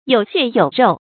有血有肉 注音： ㄧㄡˇ ㄒㄧㄝ ˇ ㄧㄡˇ ㄖㄡˋ 讀音讀法： 意思解釋： 有生命；有活力；形容文藝作品形象生動；內容充實。